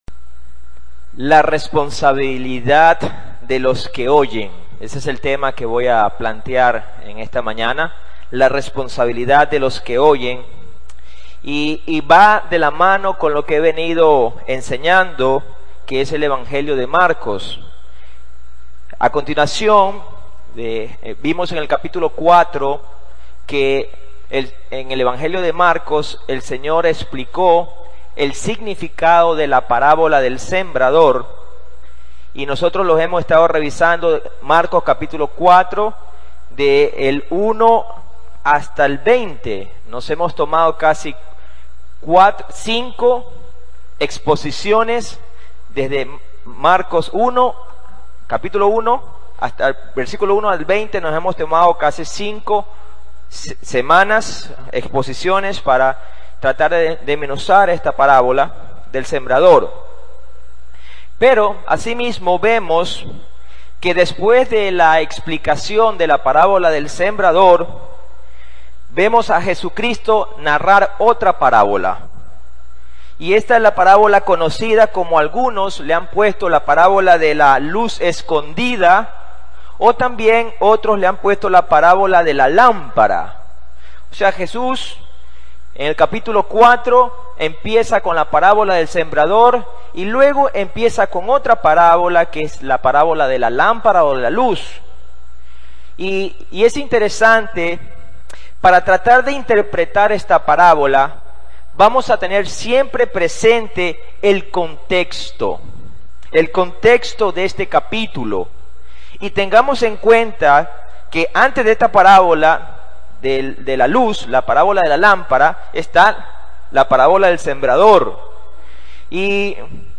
Audio del sermón
Iglesia Cristiana Torre Fuerte de Guayaquil Serie: Evangelio de Marcos 4:21-25